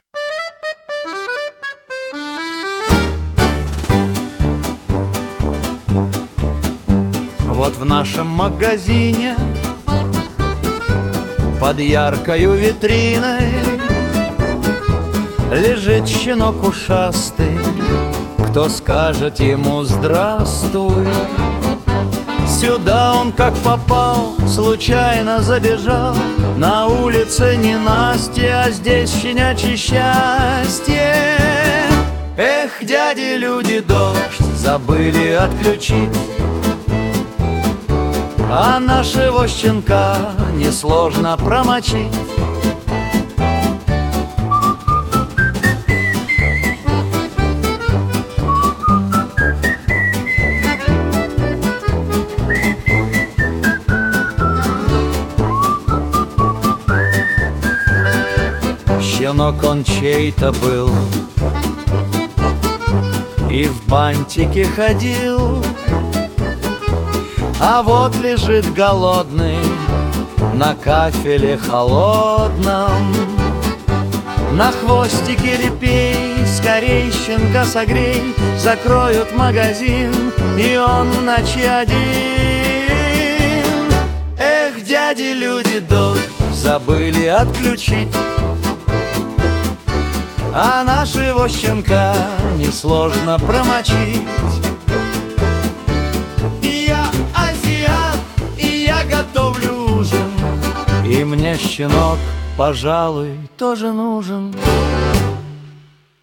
• Жанр: Комедия